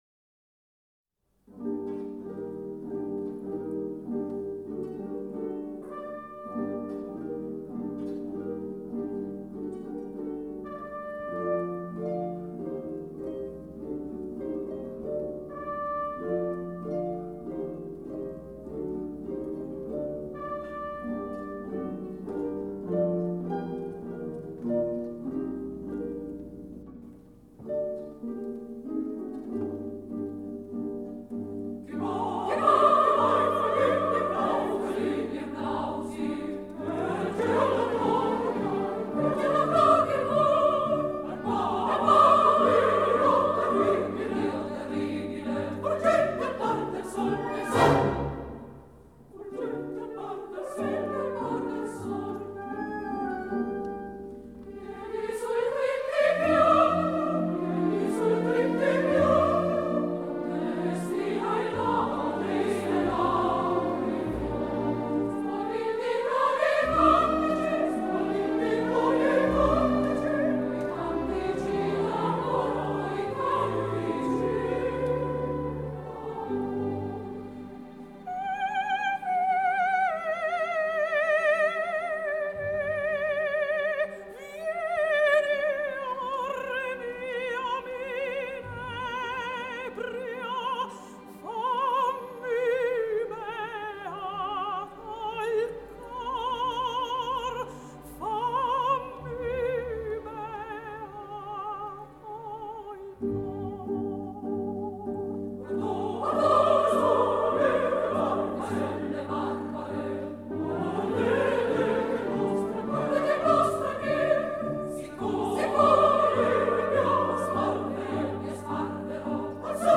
Запись 3-16 IX. 1955, театр "Ла Скала", Милан.